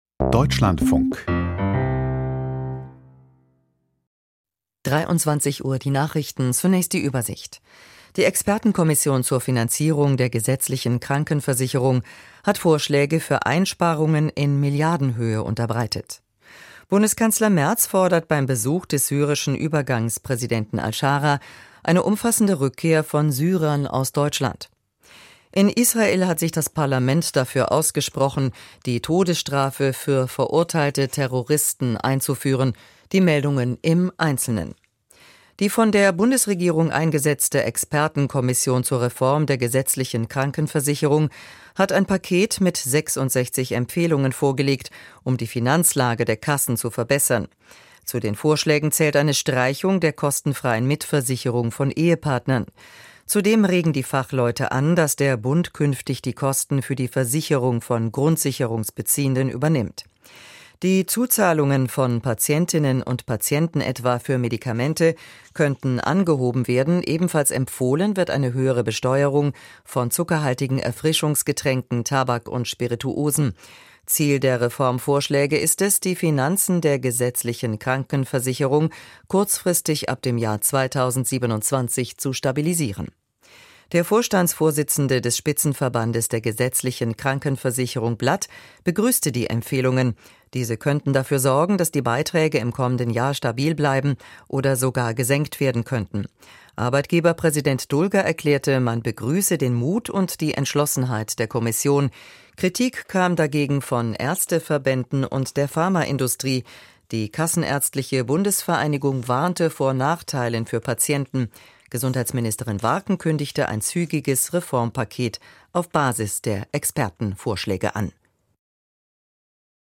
Die Nachrichten vom 30.03.2026, 23:00 Uhr